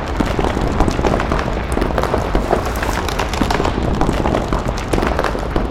earthloop.wav